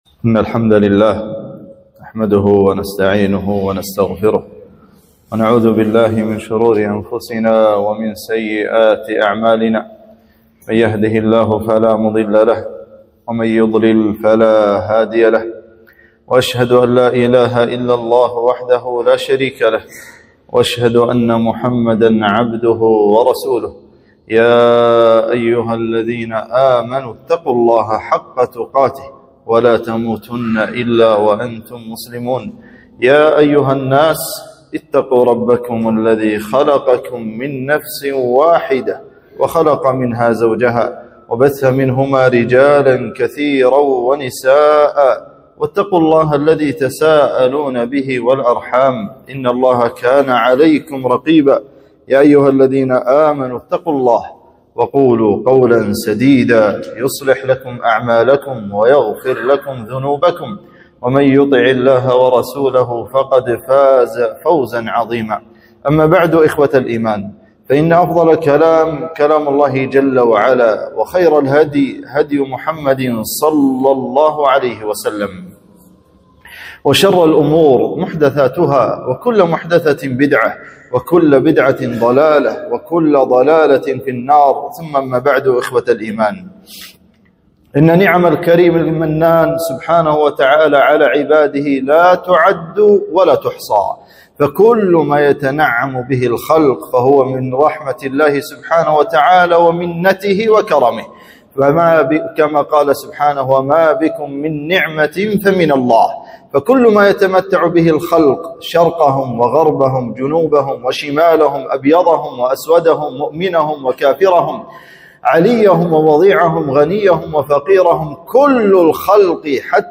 خطبة - نعم الله